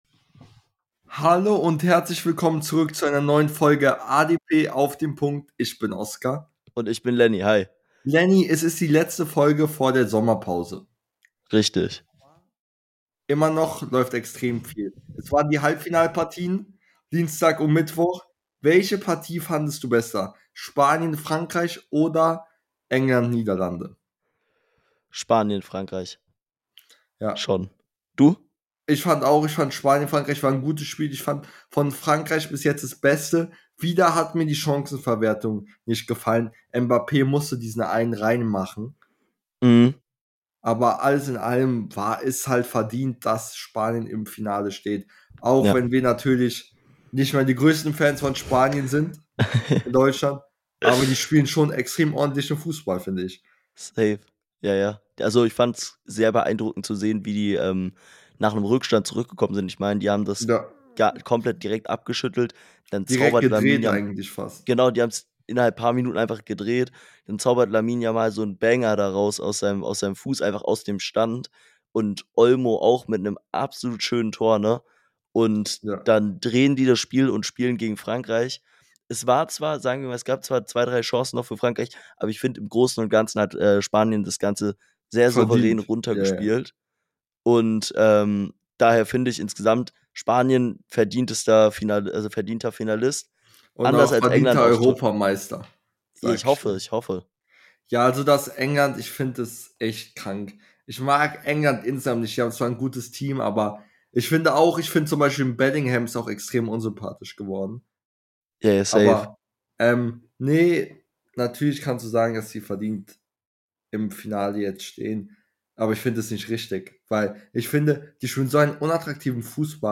In der heutigen Folge reden die beiden Hosts über die Transfers der Bundesligaklubs , tippen das Finale und verabschieden sich in die Sommerpause